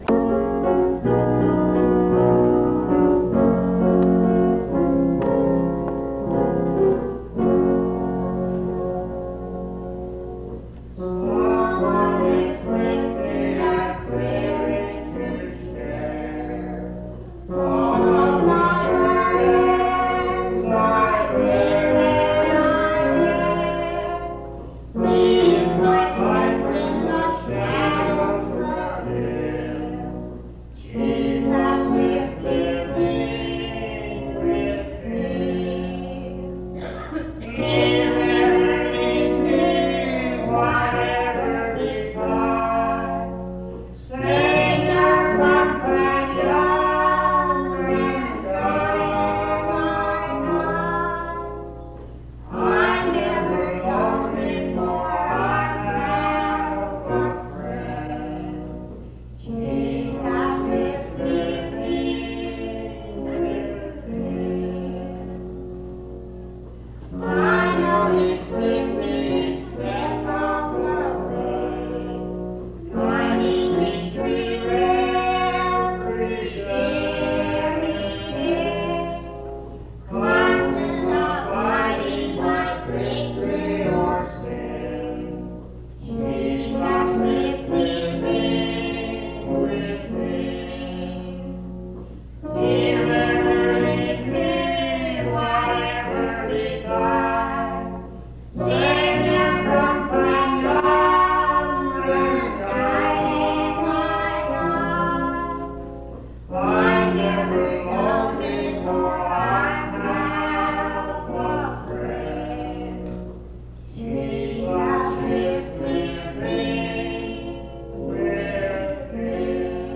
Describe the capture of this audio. This recording is from the Monongalia Tri-District Sing.